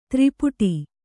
♪ tri puṭi